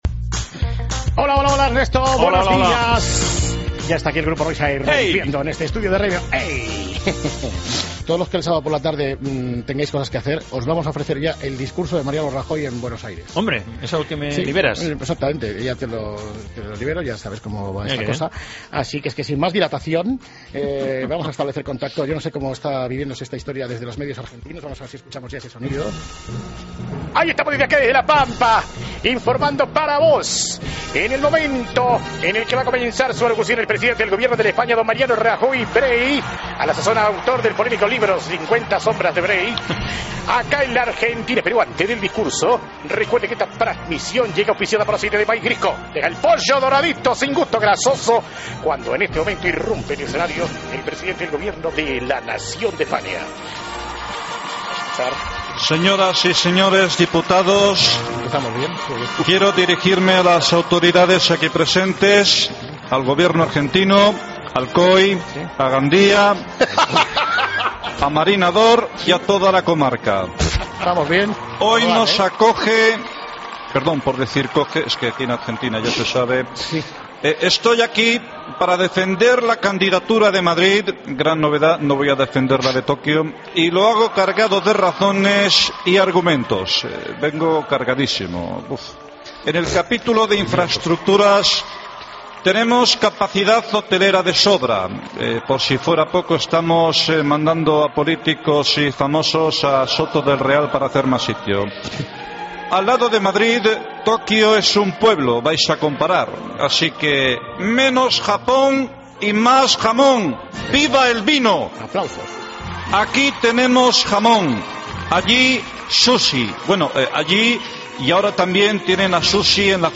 Discurso Rajoy JJOO